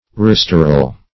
restoral - definition of restoral - synonyms, pronunciation, spelling from Free Dictionary Search Result for " restoral" : The Collaborative International Dictionary of English v.0.48: Restoral \Re*stor"al\ (-al), n. Restoration.